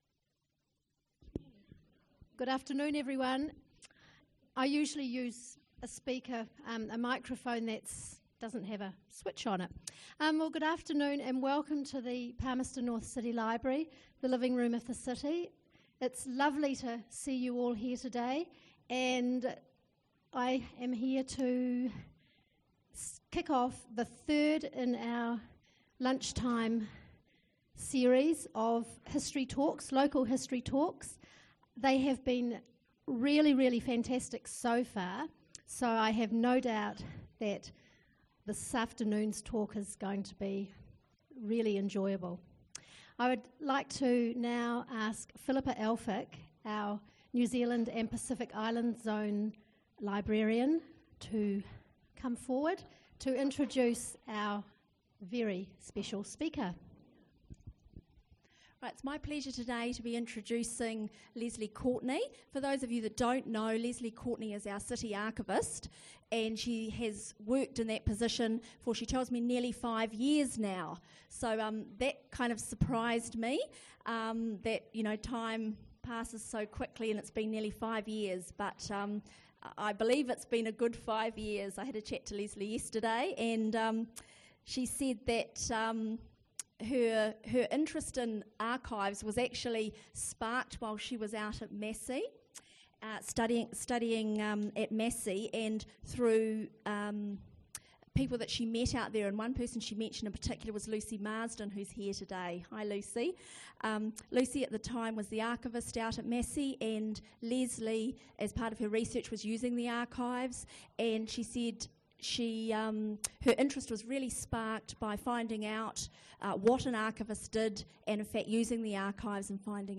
The Houses of Massey – Talk - Manawatū Heritage